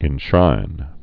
(ĭn-shrīn)